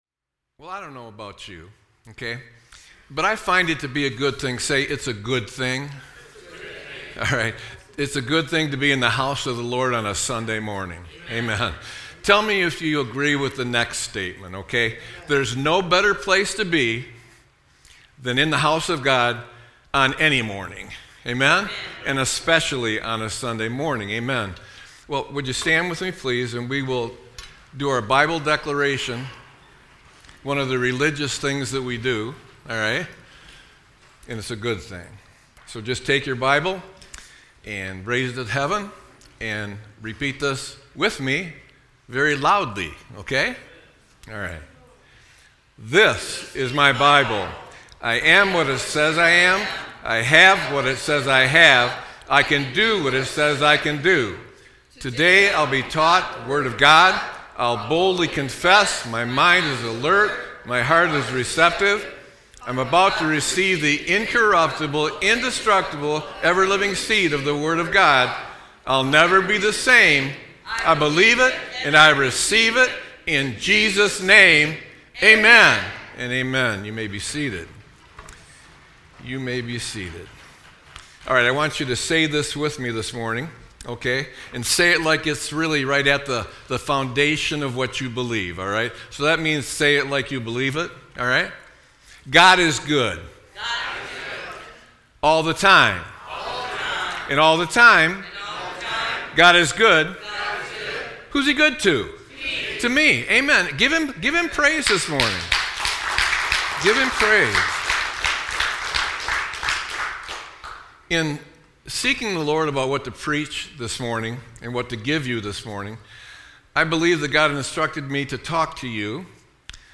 Sermon-8-24-25.mp3